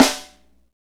SNR FNK S09R.wav